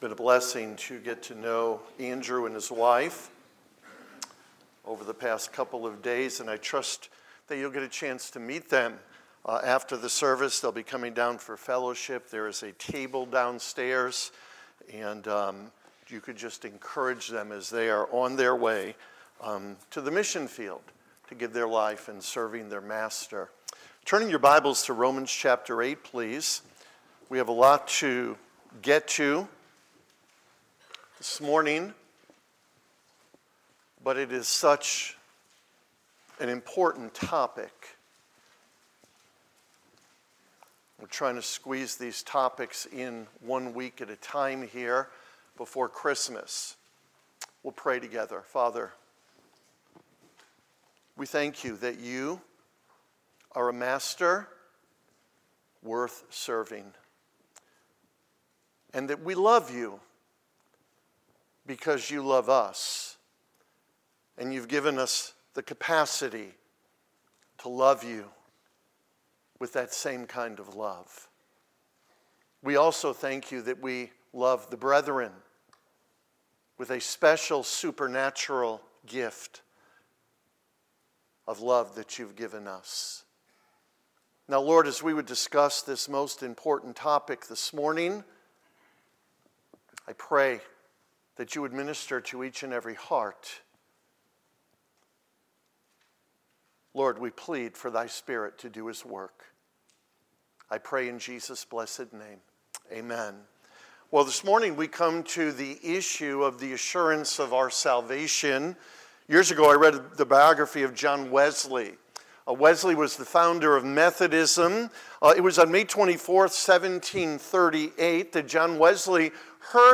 12.1.24-Sermon.mp3